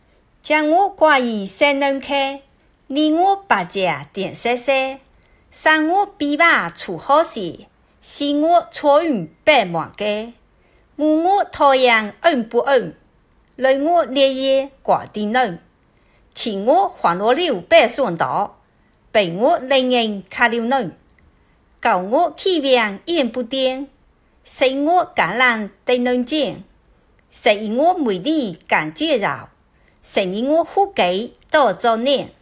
十二月果子歌朗诵版 | 福州话资源网
十二月果子歌朗诵版.0d863.m4a